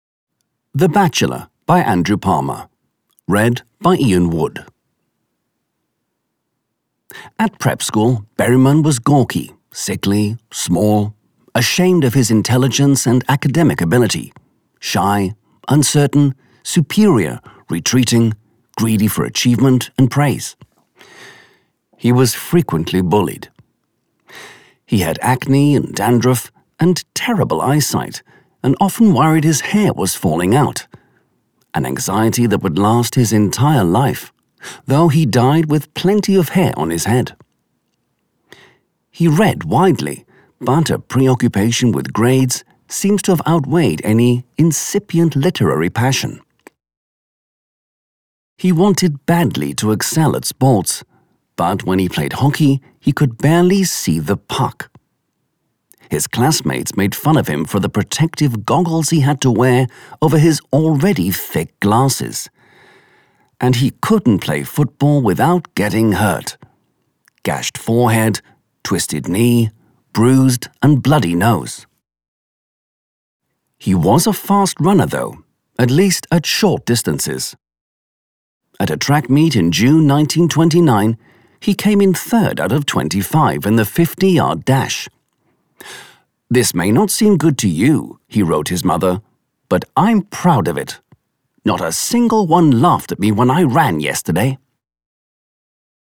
sehr variabel
Mittel minus (25-45)
Audiobook (Hörbuch)